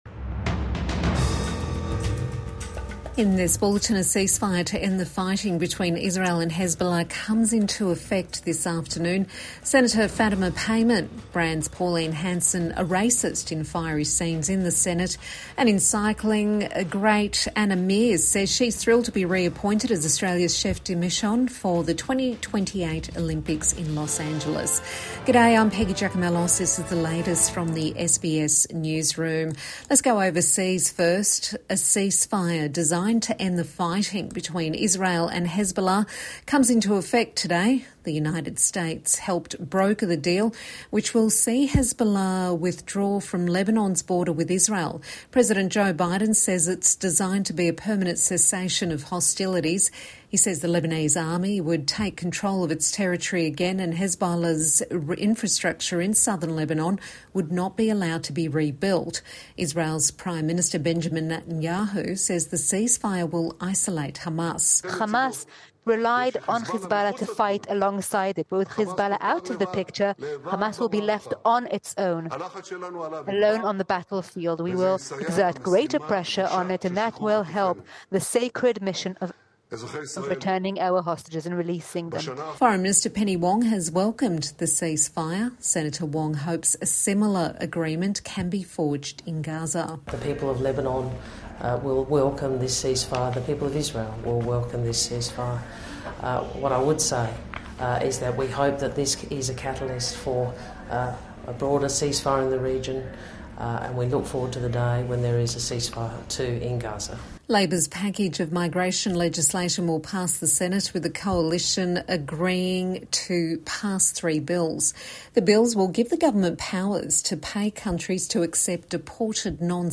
Midday News Bulletin 27 November 2024